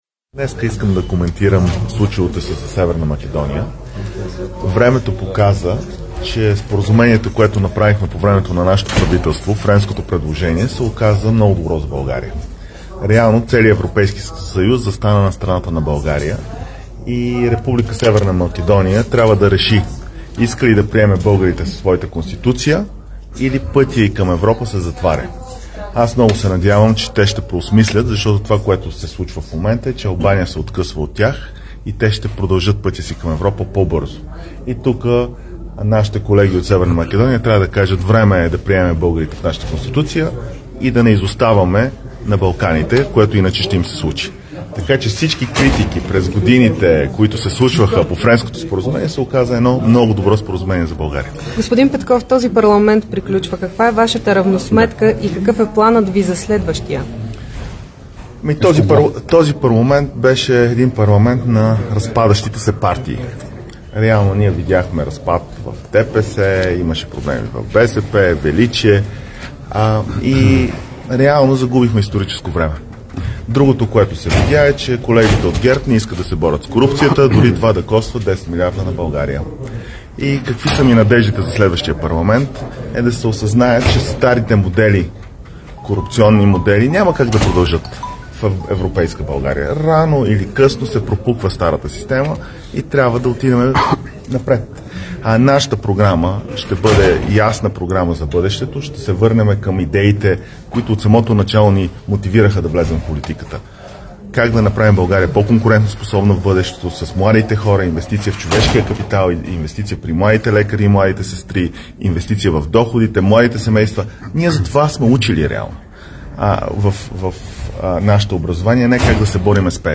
10.30 - Брифинг на председателя на ПГ на ИТН Тошко Йорданов и народния представител от ИТН Станислав Балабанов за промените в Закона за закрила на детето. - директно от мястото на събитието (Народното събрание)
Директно от мястото на събитието